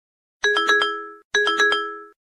Категория: Звуки